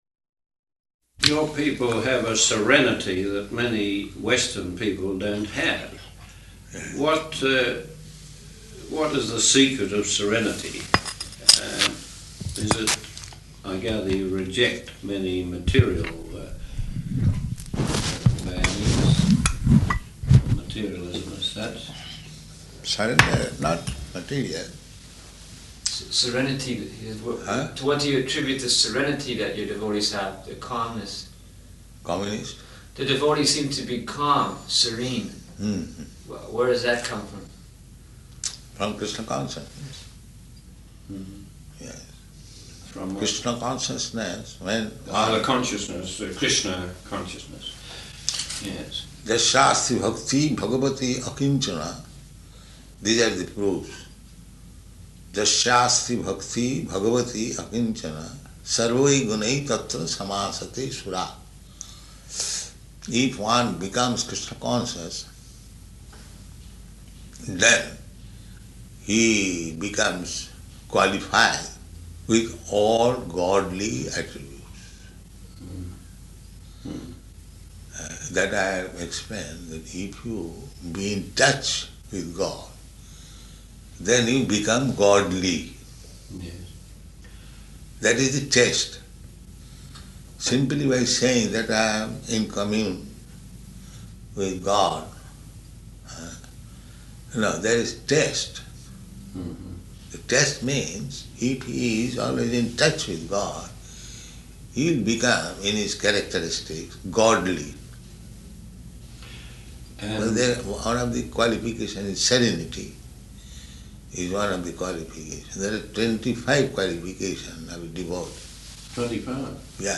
Type: Conversation
Location: Melbourne